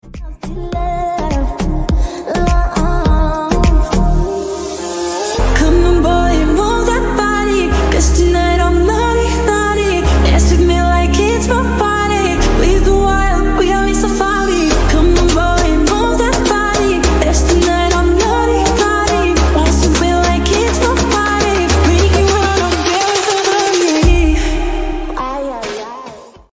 Jimin voice like Angel 🫦 sound effects free download